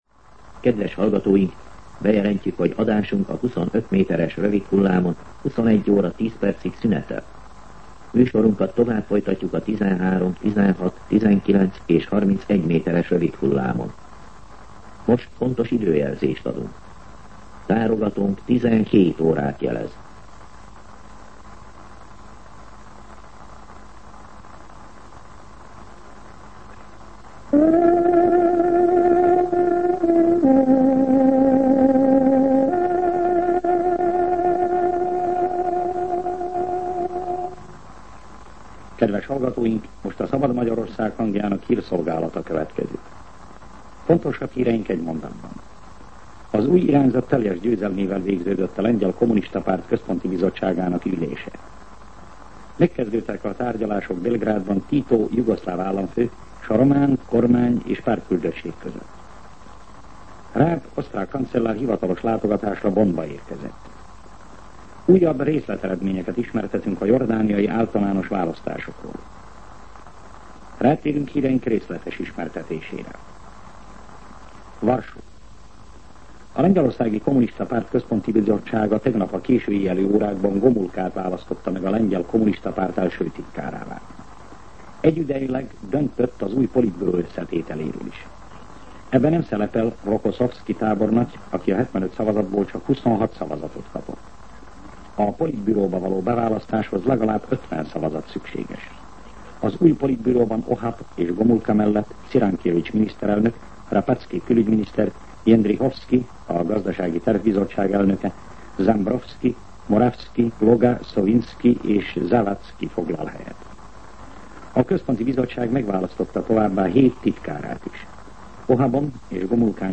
17:00 óra. Hírszolgálat